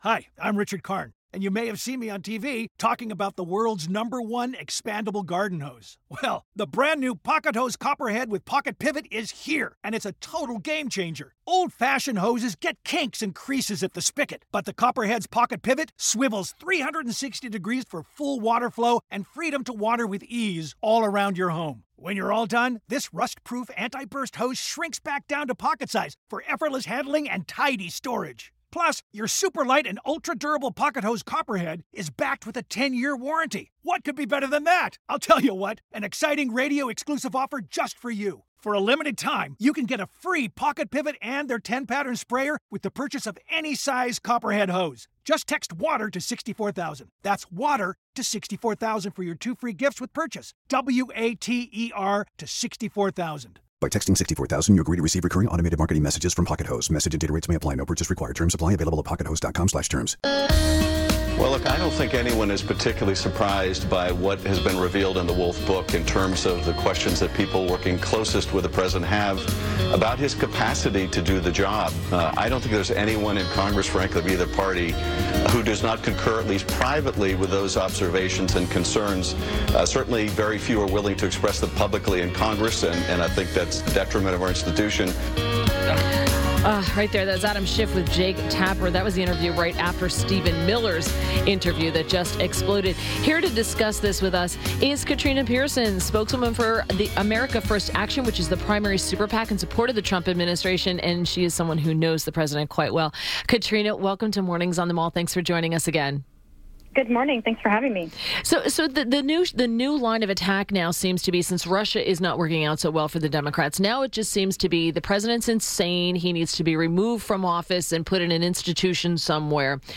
INTERVIEW - KATRINA PIERSON - Spokeswoman for America First Action, the primary super PAC in support of the Trump administration – shared her thoughts on the Steve Bannon comments in the Michael Wolff book and questions about President Trump’s mental fitness.